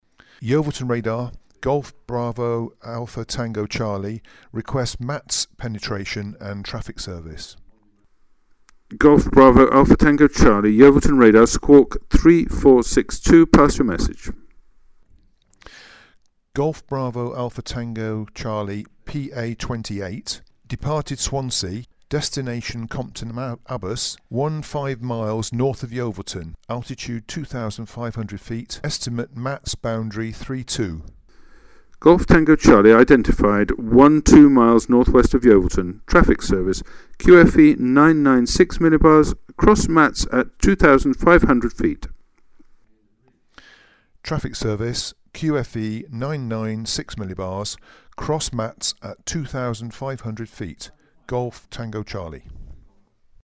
Listen to the relevant exchanges between pilot and ground (links are in the text).
Audio 4. Now the pilot needs to talk to Yeovilton in order to seek clearance through the MATZ .